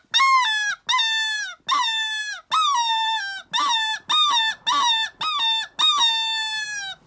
The OAKWOOD Fox Call
Oakwood-Fox.mp3